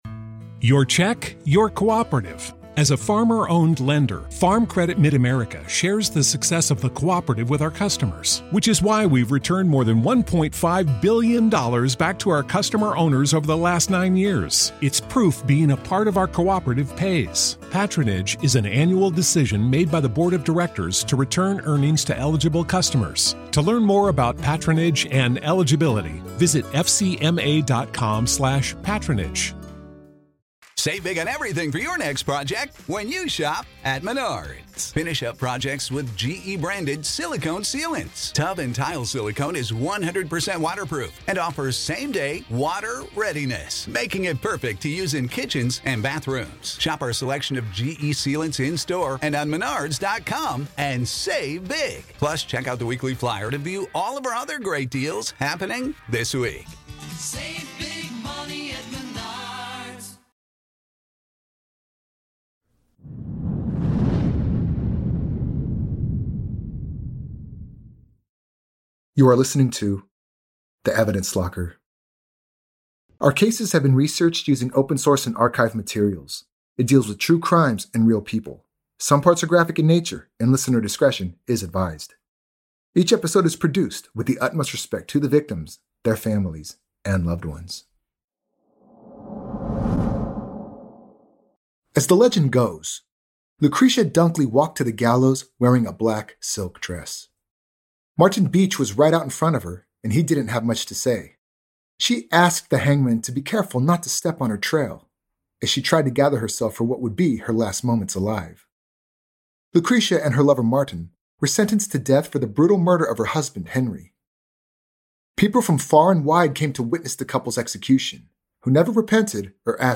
True Crime, Society & Culture, Documentary, History